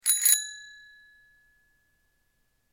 جلوه های صوتی
دانلود صدای دوچرخه 1 از ساعد نیوز با لینک مستقیم و کیفیت بالا